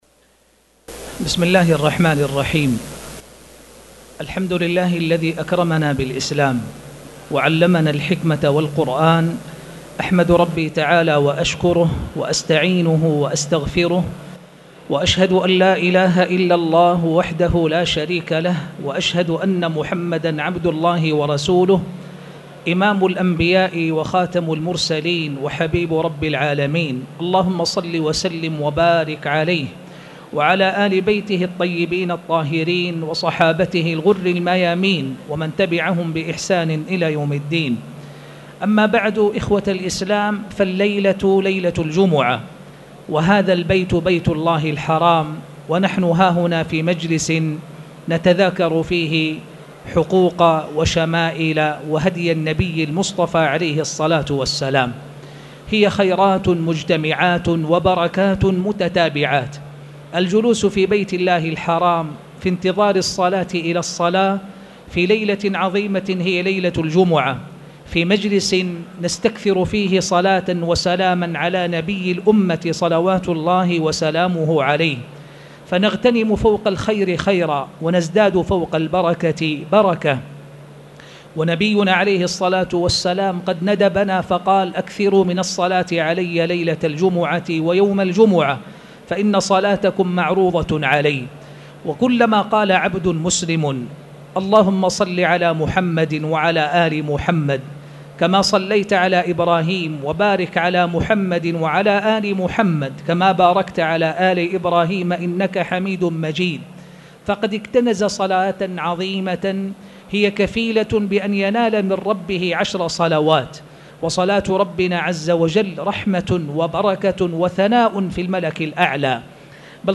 تاريخ النشر ٣ جمادى الآخرة ١٤٣٨ هـ المكان: المسجد الحرام الشيخ